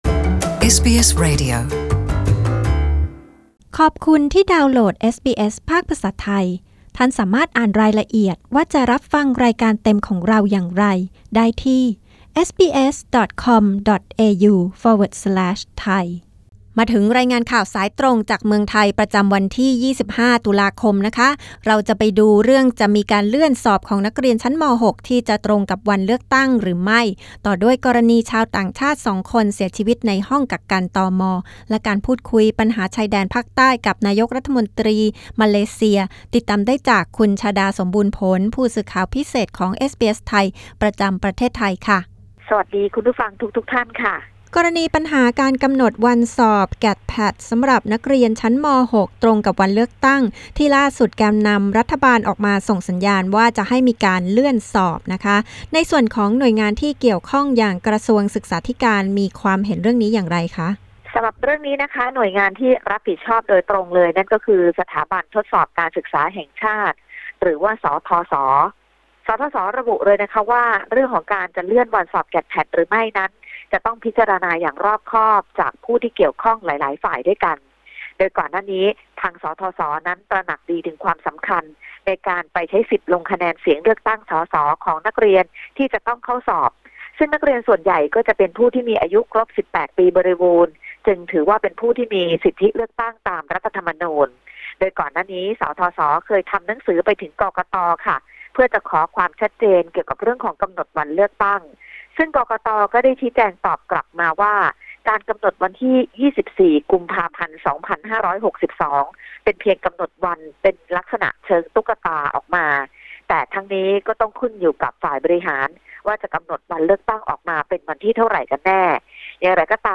Thai news report Oct 25